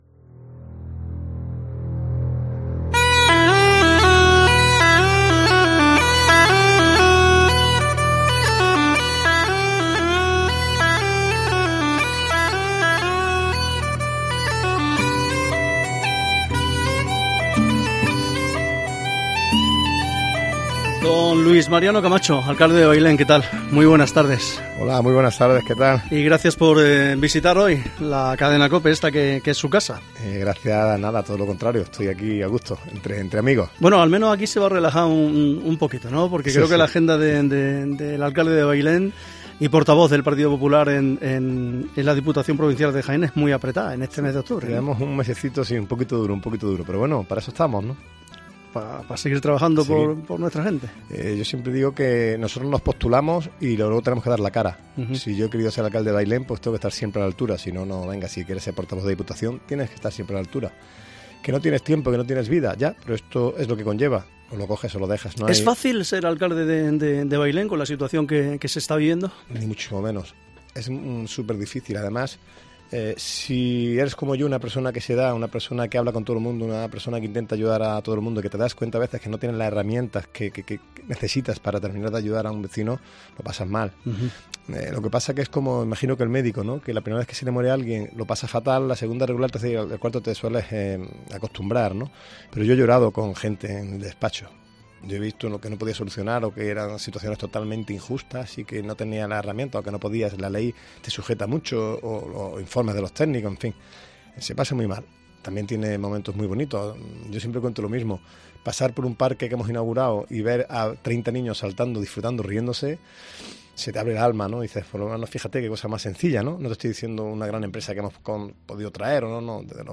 Charlamos con Luis Mariano Camacho, alcalde de Bailén